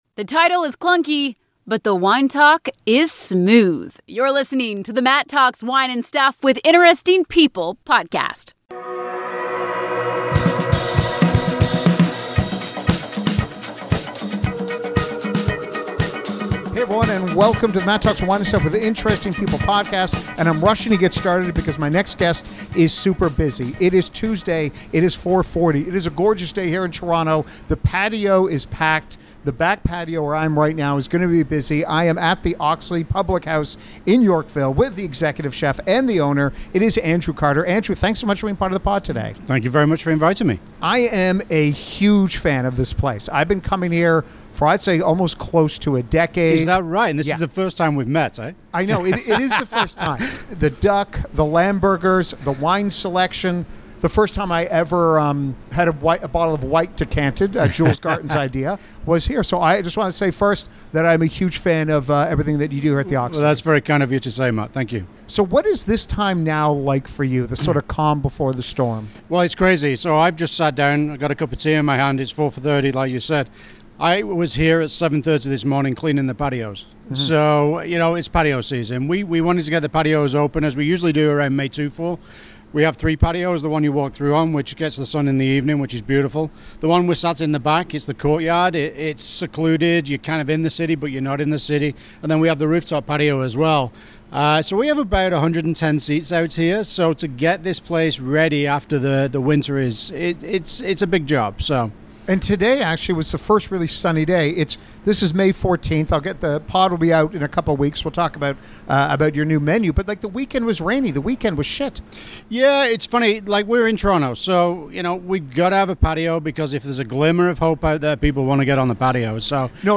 A powerhouse of an interview!!